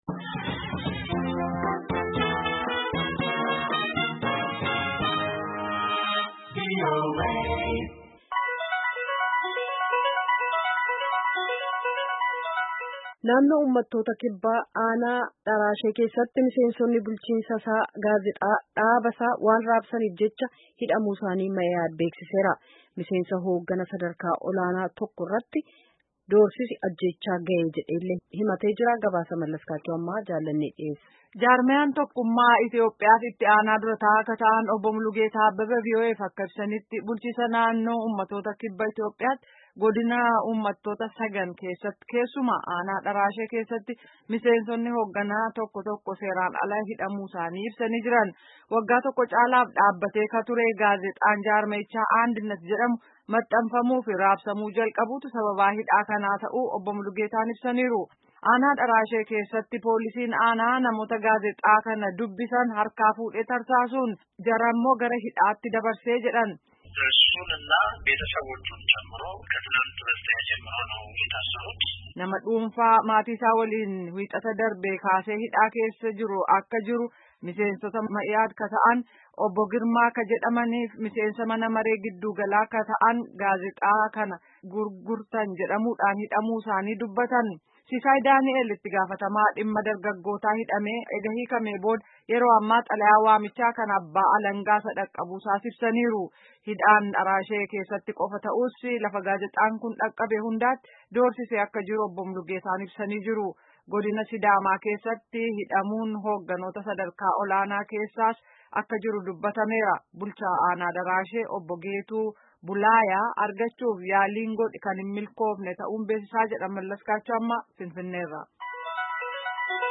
Gabaasa ergamee caqasaa.